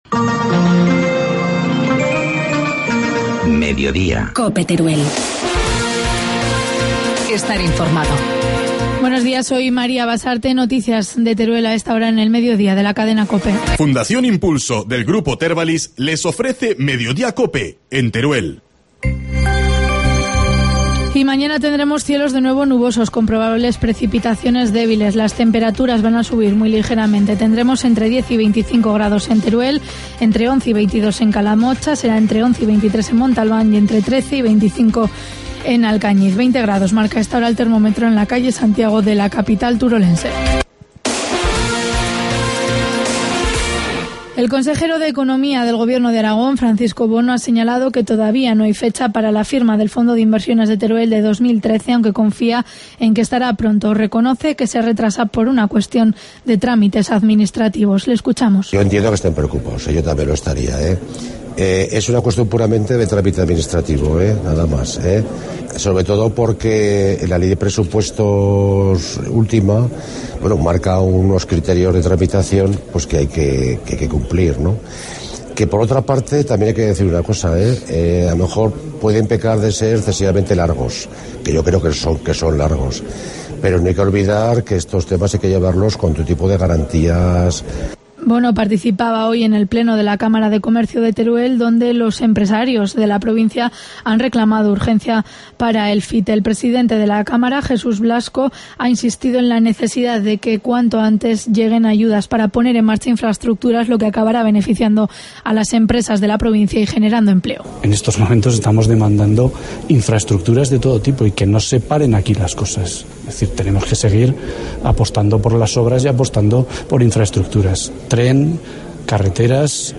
Informativo mediodía, miércoles 8 de mayo